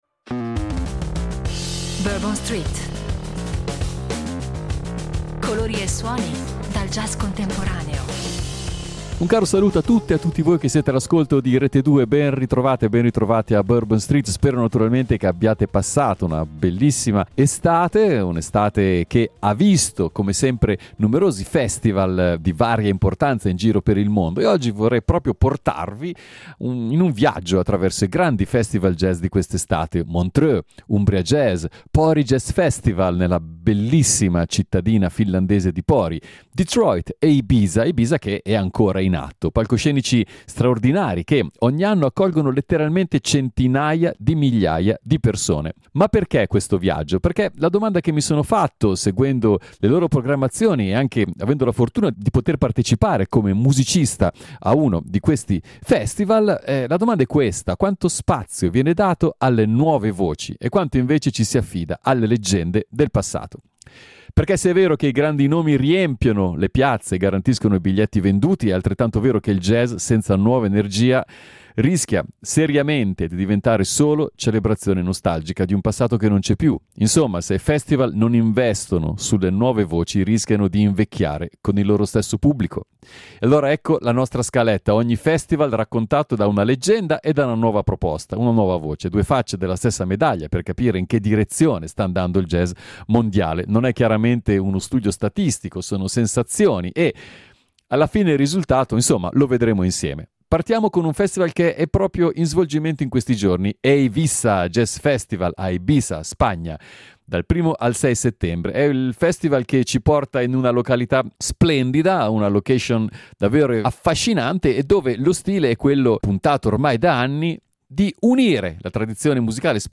Uno speciale in forma di reportage, dove musica e riflessione si intrecciano per mostrare che il jazz, oggi più che mai, resta un linguaggio vivo, in continua trasformazione.